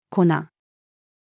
粉-flour-female.mp3